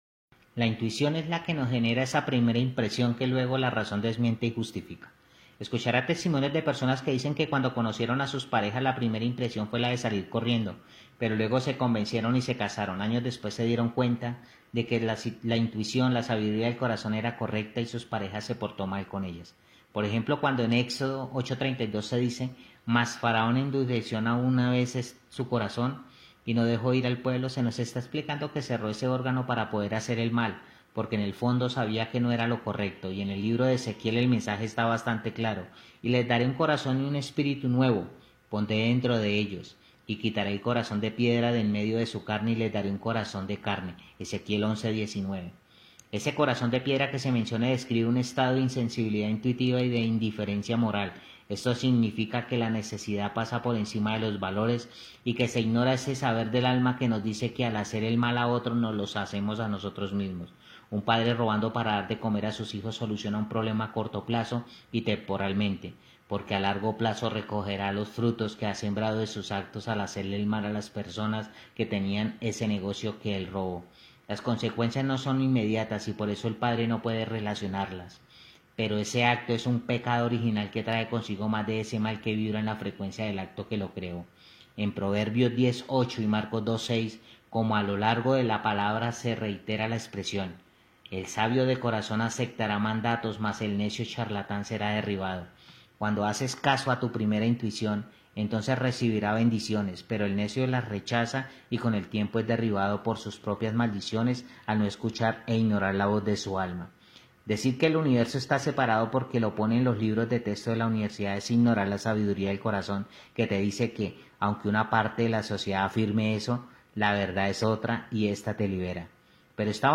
Secretos Revelados – Audio Libro Cap 2.